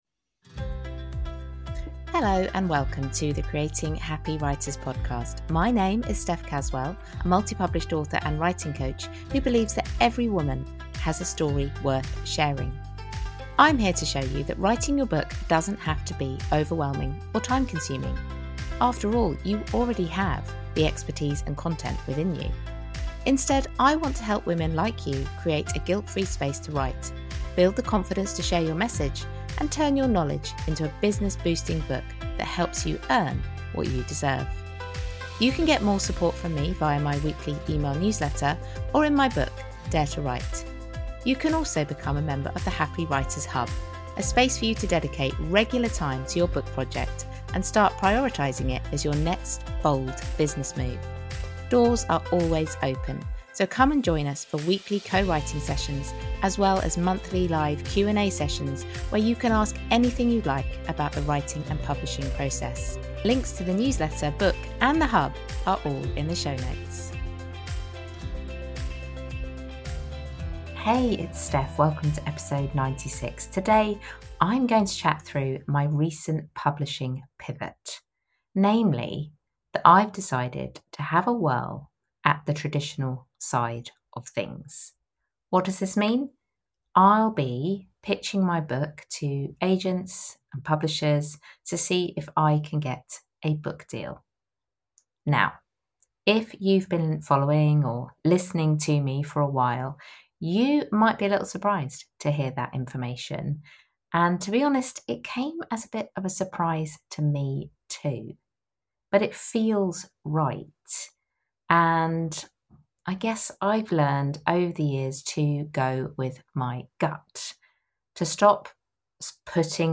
In this solo episode, I’m pulling back the curtain on a decision that’s surprised even me: exploring traditional publishing for my next book.